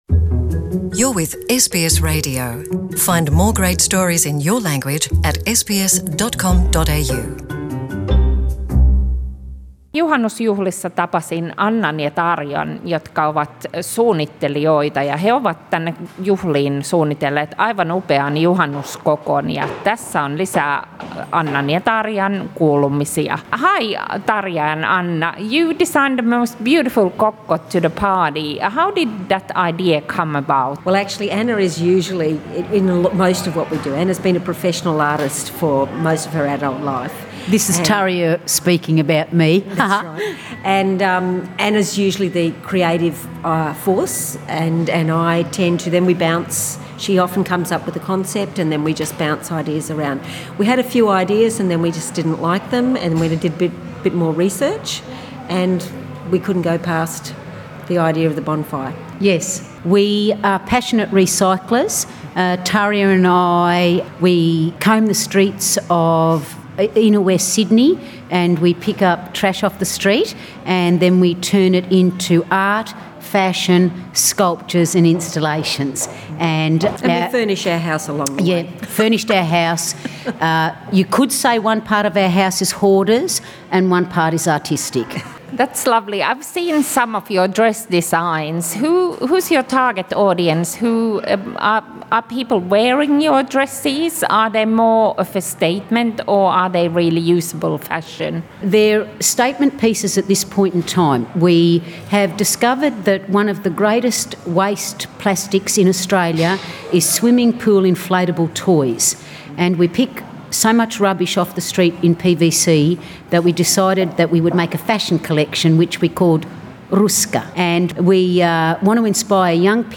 He kasasivat kokoon kauniin kierrätyskokon Sydneyn Suomi-Seuran järjestämille juhannusjuhlille. Haastattelu on englanniksi.